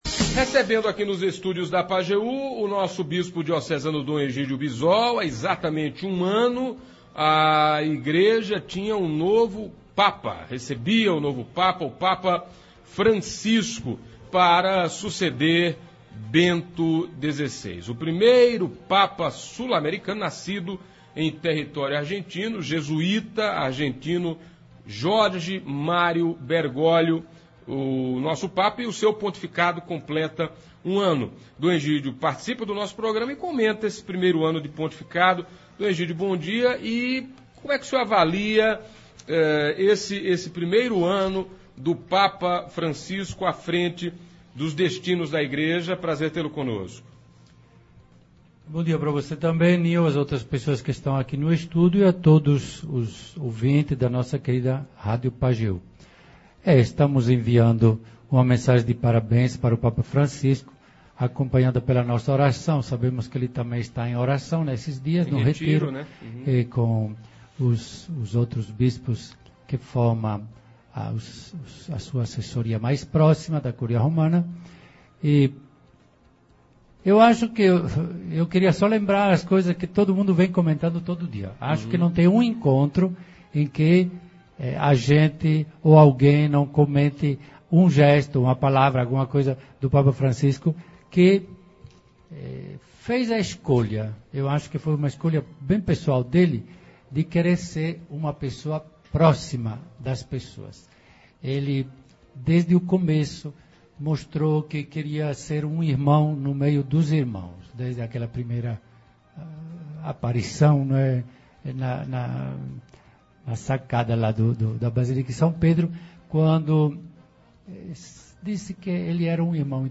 Hoje (13) durante o programa Manhã Total, Dom Edígio Bisol falou sobre o primeiro ano do Papa Francisco ha frente da Igreja Católica Apostólica Romana.